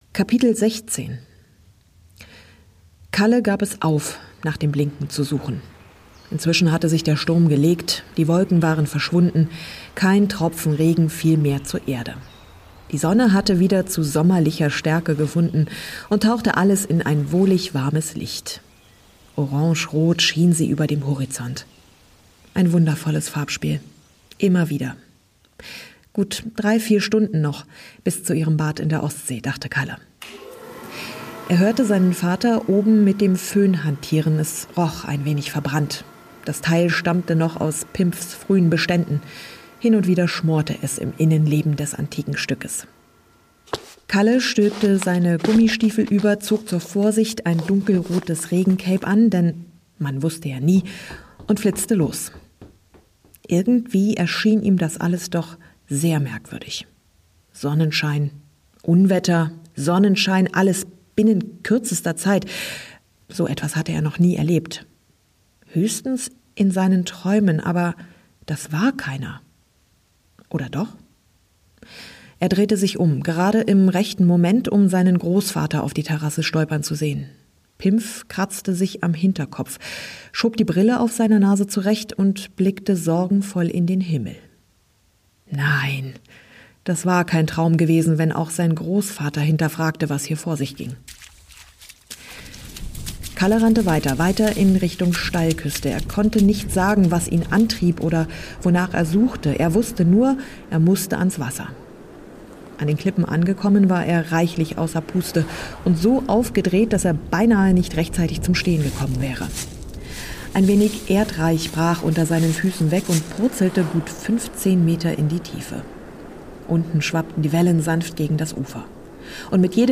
Ein atmosphärisches Hörerlebnis für alle, die sich gern davon und in die Wolken träumen.